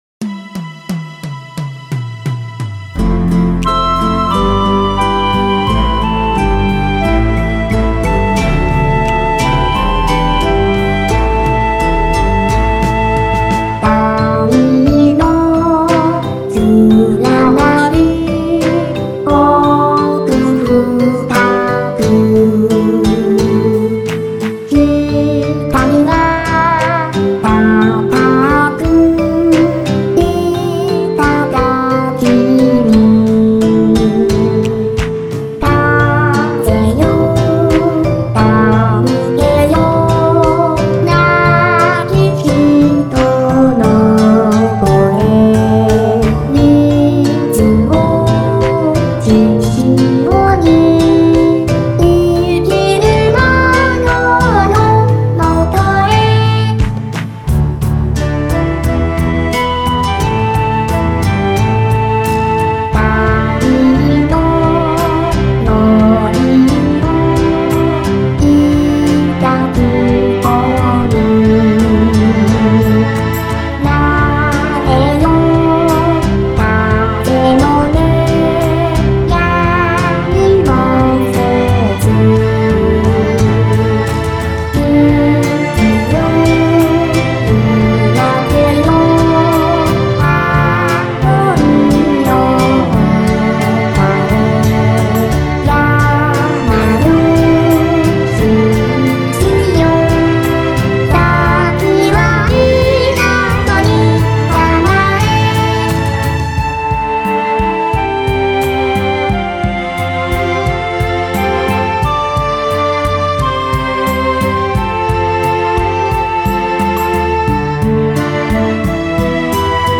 【2.62MB　2:17】イメージ：歌つき、和風、田舎